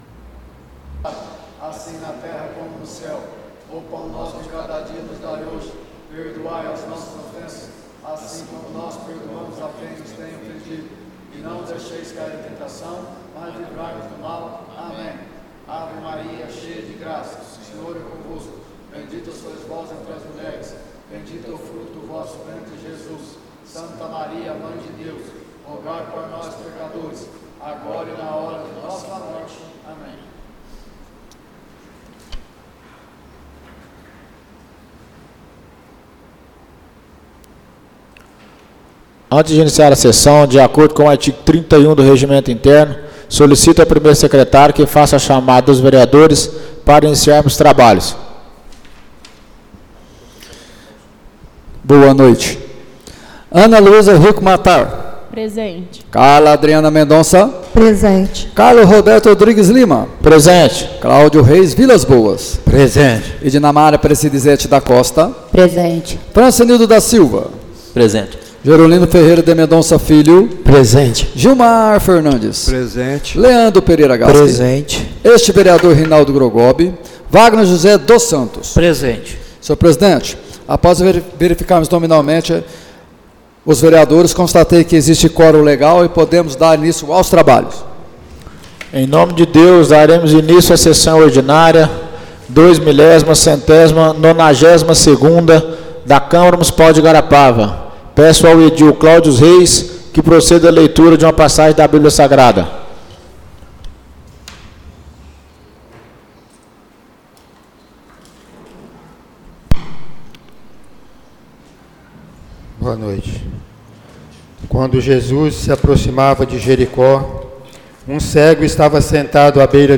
Áudio da Sessão Ordinária de 18/11/2024